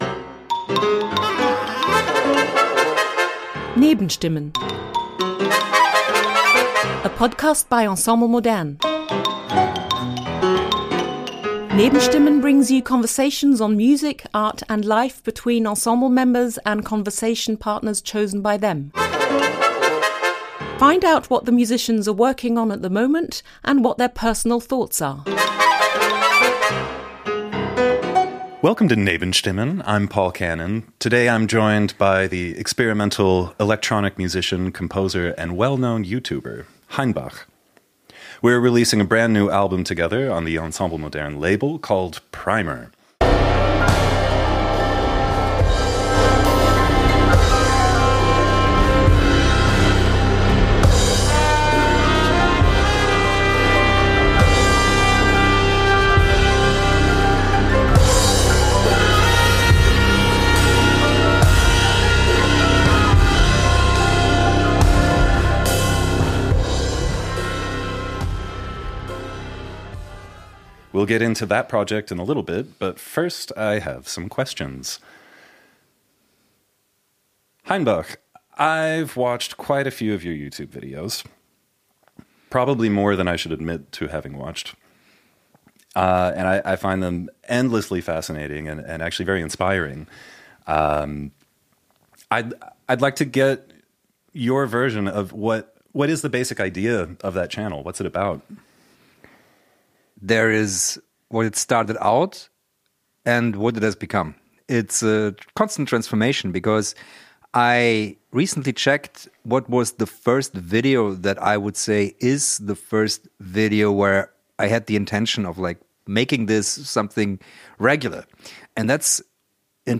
In “Nebenstimmen” hören Sie Gespräche von Ensemble-Mitgliedern mit von ihnen selbst ausgewählten Gesprächspartnerinnen und -partnern zu Musik, Kunst und Leben.